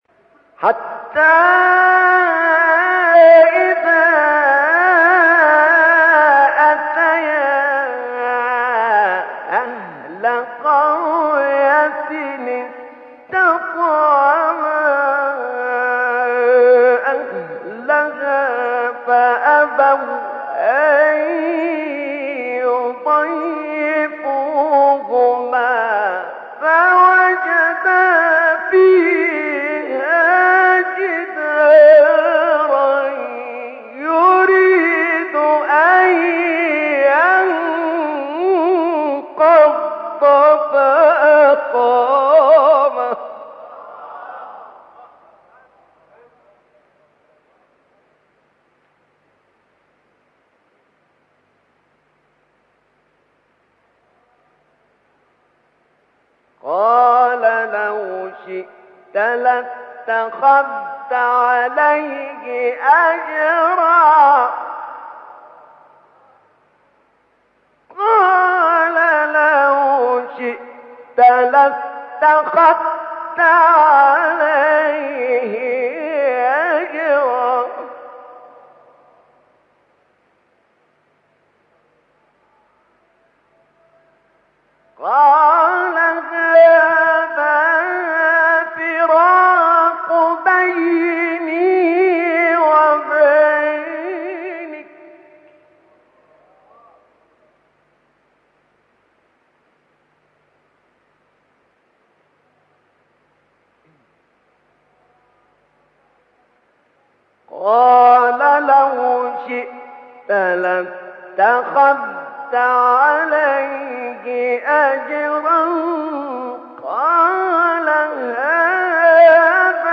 آیه 77-78 سوره کهف استاد نعینع | نغمات قرآن | دانلود تلاوت قرآن